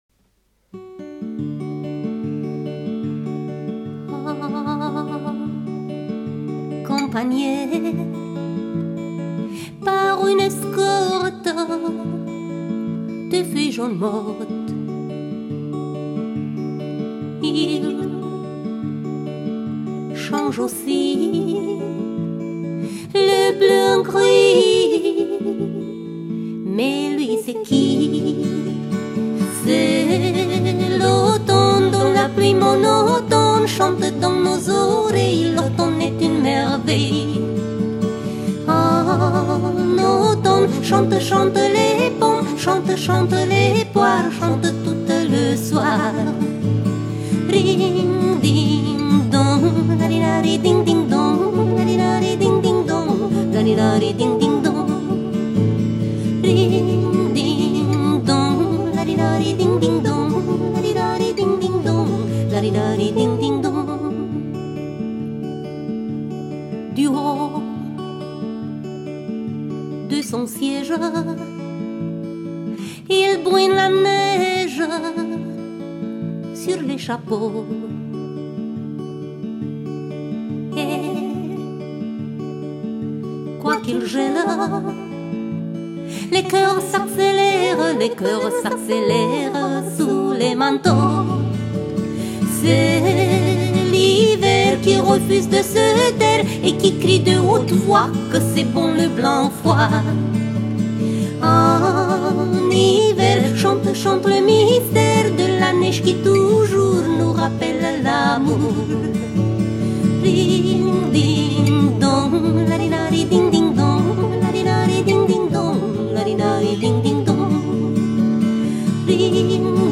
musique, paroles, voix et guitare